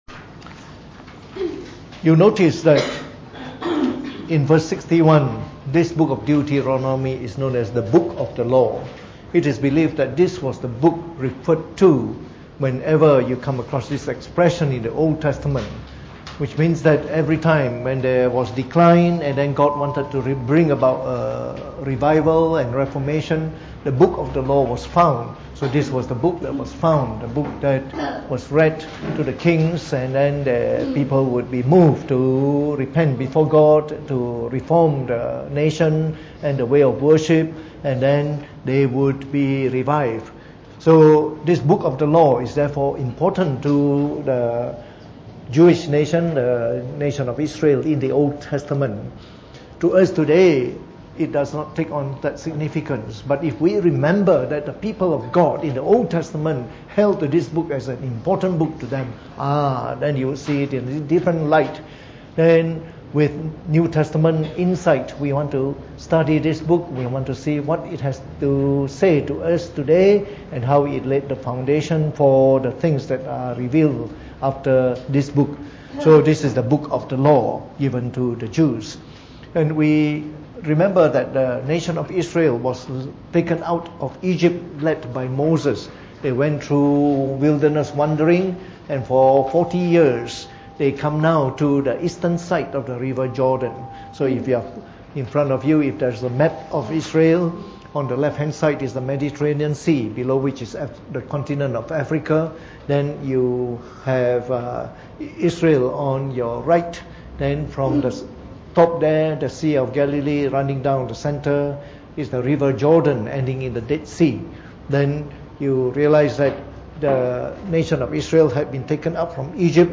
Preached on the 26st of September 2018 during the Bible Study, from our series on the book of Deuteronomy.